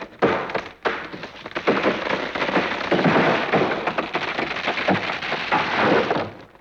Index of /90_sSampleCDs/E-MU Producer Series Vol. 3 – Hollywood Sound Effects/Human & Animal/Falling Branches